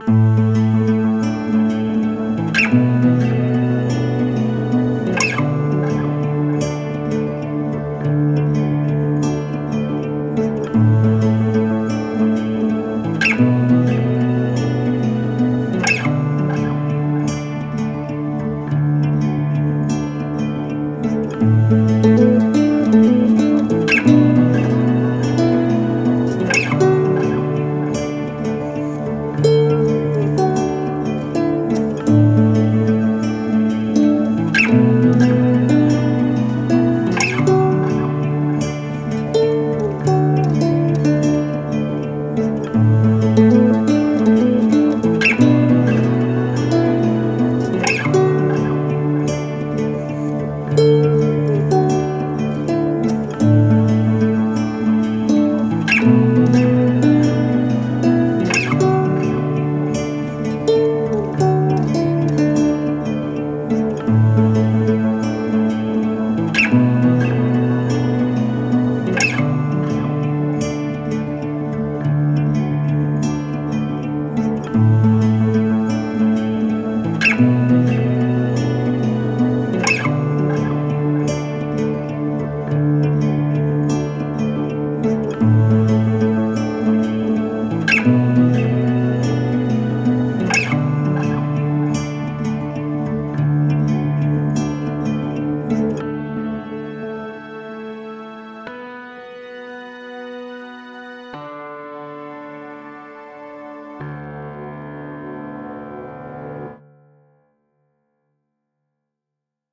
Oggi ho deciso di condividere con il mondo di wattpad una traccia musicale composta da chitarra classica, pianoforte ed archi; gli ultimi due strumenti citati sono digitali. È una delle mie prime composizioni, infatti fa un pò schifo, ma volevo comunque condividerla con voi, senza nessun valido motivo, solo per il piacere di farlo.